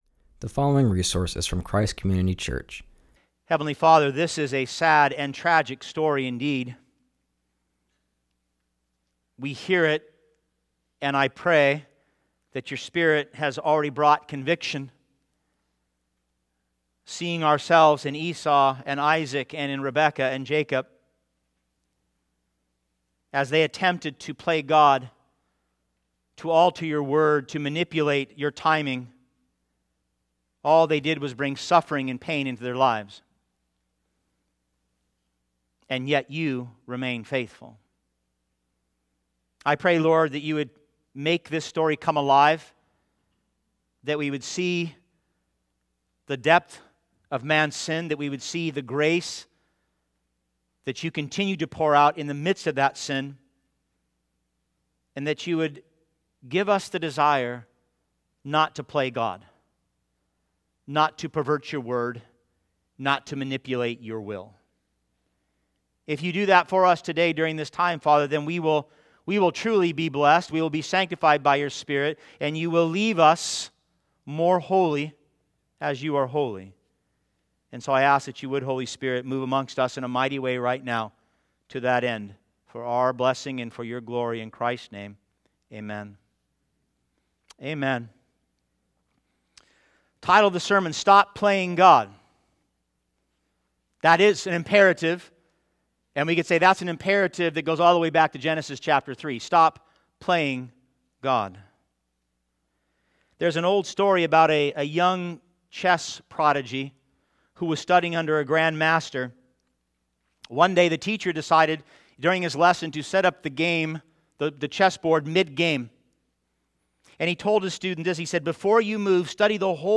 Stop Playing God - Genesis 27:1-40 | Christ Community Church of San Jose